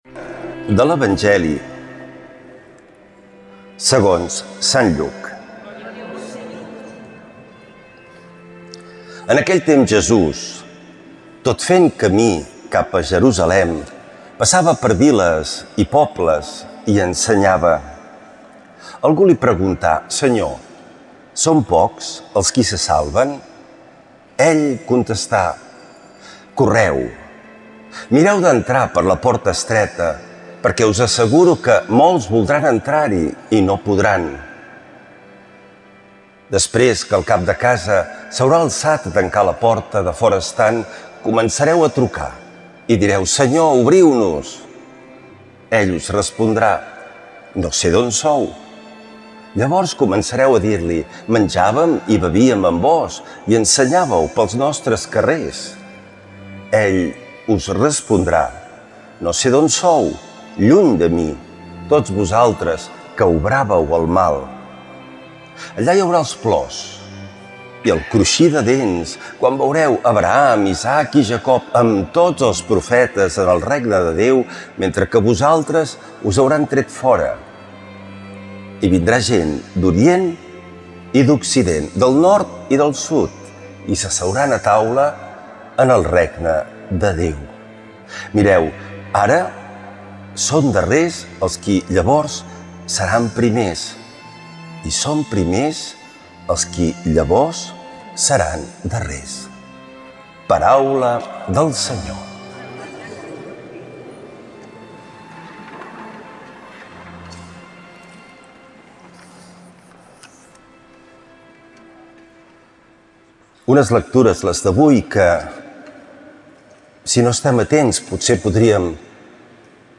Lectura de l’evangeli segons sant Lluc